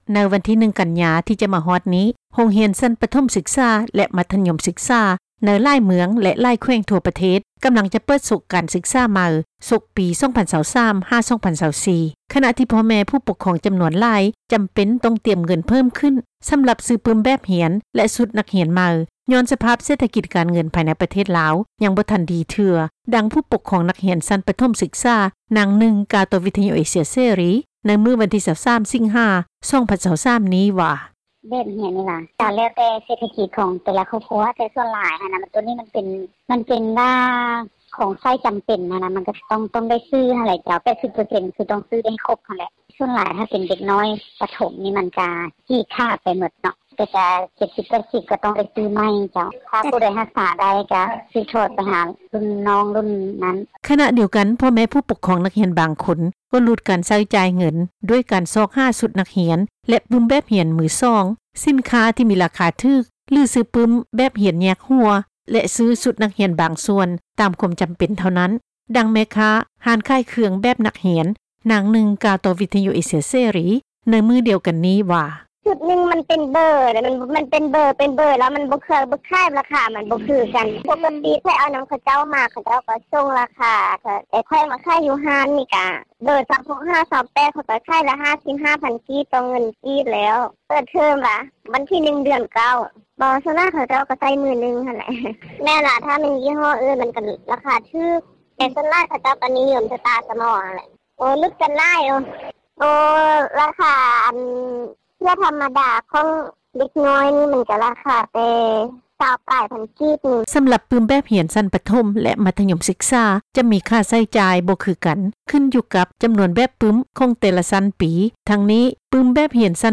ດັ່ງ ຜູ້ປົກຄອງນັກຮຽນຊັ້ນ ປະຖົມສຶກສາ ນາງນຶ່ງ ກ່າວຕໍ່ວິທຍຸ ເອເຊັຽ ເສຣີ ໃນມື້ວັນທີ 23 ສິງຫາ 2023 ນີ້ວ່າ:
ດັ່ງ ແມ່ຄ້າຮ້ານຂາຍເຄື່ອງ ແບບນັກຮຽນນາງນຶ່ງ ກ່າວຕໍ່ ວິທຍຸເອເຊັຽ ເສຣີ ໃນມື້ດຽວກັນນີ້ວ່າ:
ດັ່ງ ເຈົ້າໜ້າທີ່ ທີ່ເຮັດວຽກ ດ້ານການສຶກສາ ນາງນຶ່ງ ກ່າວວ່າ: